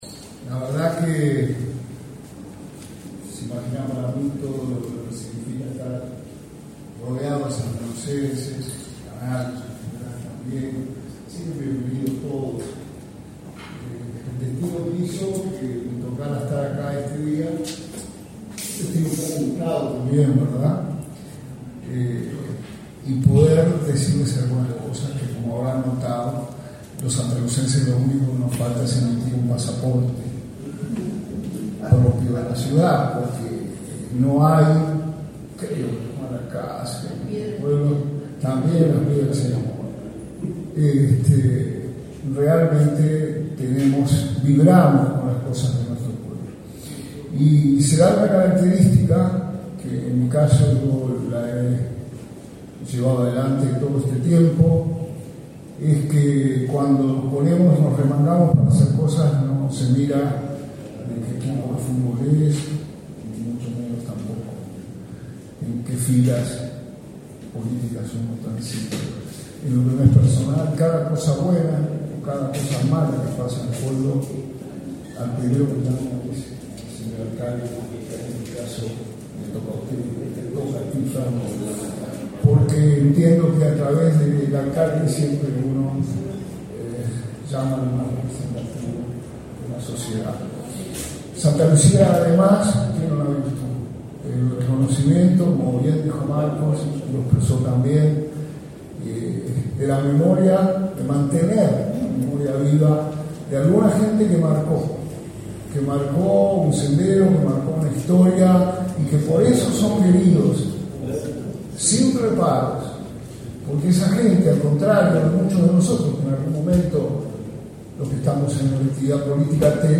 Palabras del ministro interino de Turismo, Remo Monzeglio
El Ministerio de Turismo realizó, este 23 de enero, el lanzamiento de la octava edición del Carnaval de Embarcaciones del Río Santa Lucía.
En el evento, participó el ministro interino, Remo Monzeglio.